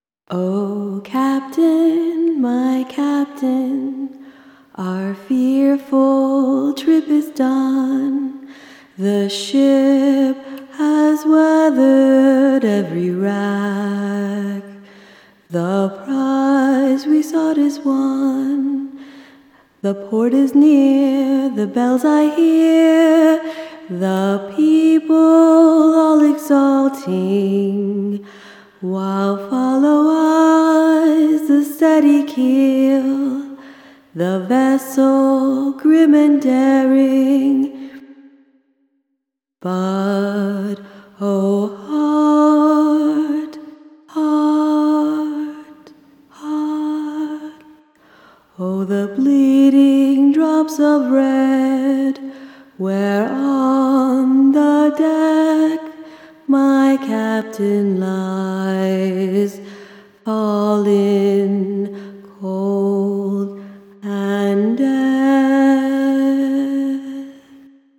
Listen for interpretation of the words, dynamics, melodic choice (which was improvised), and the possible underlying chord structure.